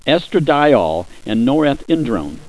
Pronunciation
(es tra DYE ole & nor eth IN drone)